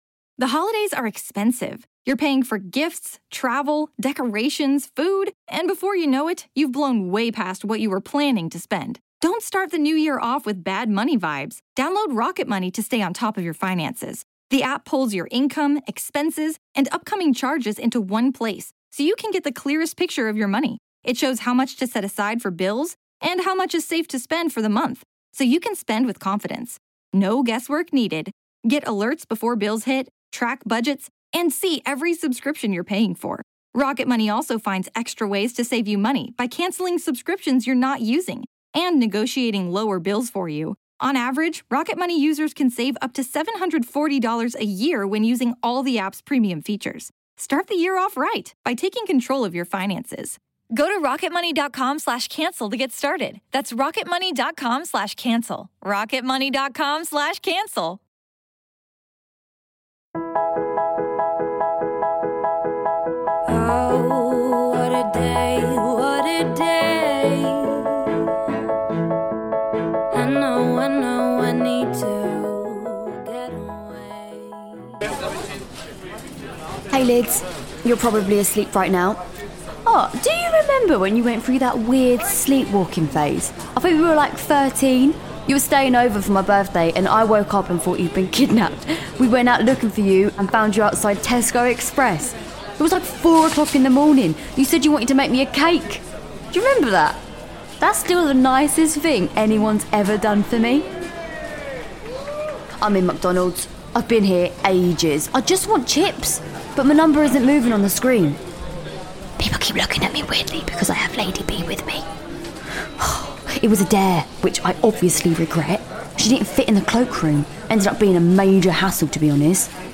Lydia is Patsy Ferran Meg is Mandeep Dhillon